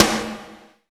12 SNARE 3-R.wav